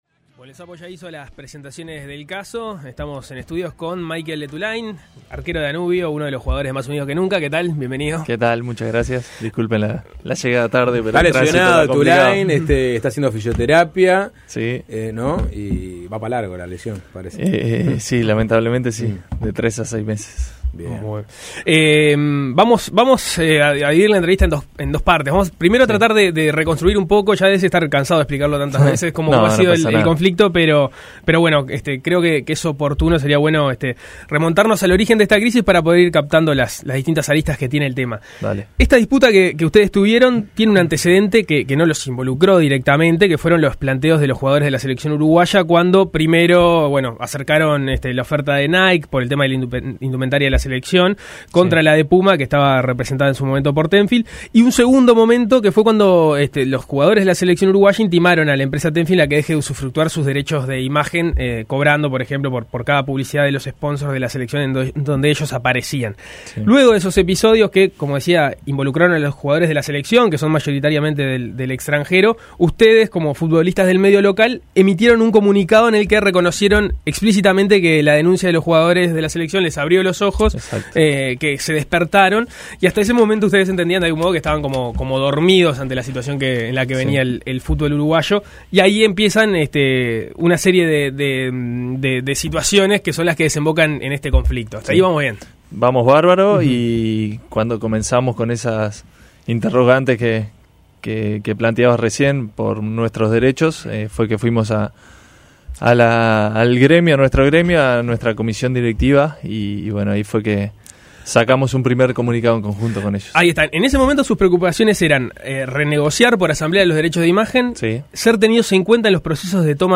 En entrevista con Suena Tremendo